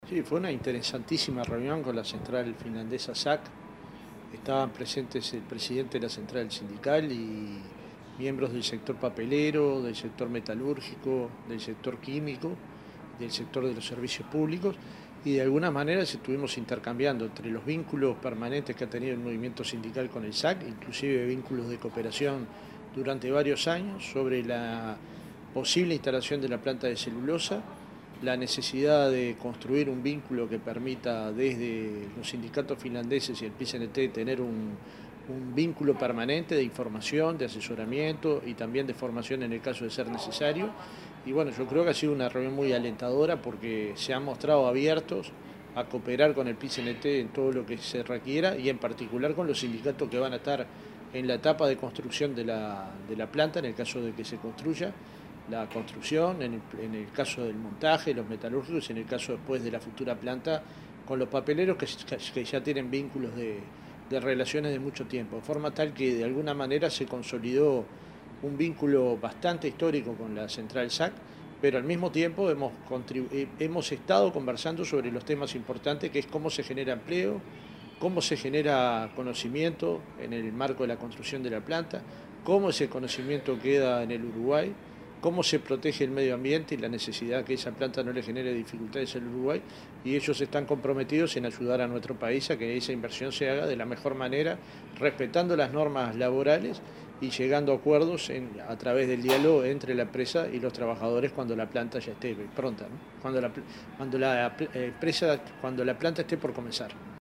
El presidente del PIT-CNT, Fernando Pereira, mantuvo esta semana una reunión con el Centro de Solidaridad Sindical de Finlandia, en el marco de la gira oficial que encabeza el presidente Vázquez. En ese contexto, dijo a la prensa que se logró consolidar un vínculo histórico y se intercambiaron opiniones sobre temas como generación de empleo y conocimiento en el marco de la posible construcción de una nueva pastera en Uruguay.